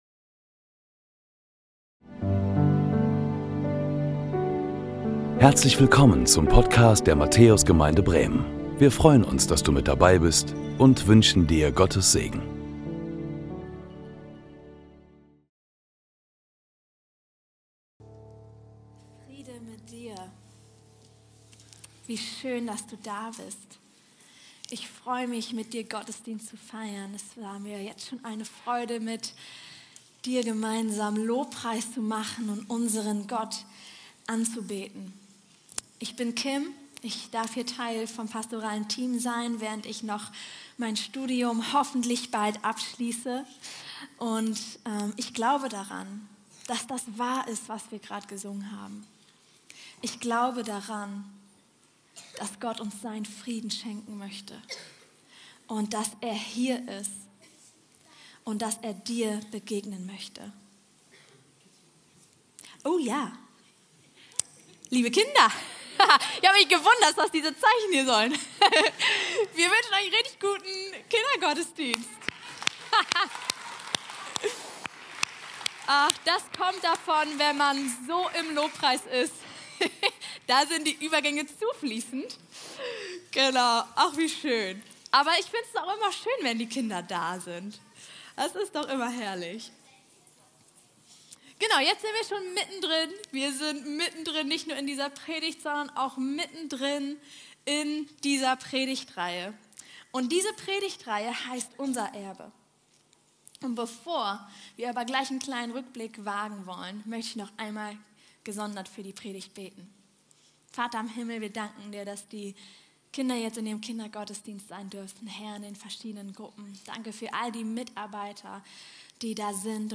Herzlich Willkommen zu unserem 2. Gottesdienst um 12 Uhr.
Predigten der Matthäus Gemeinde Bremen